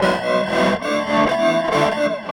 47 GUIT 2 -R.wav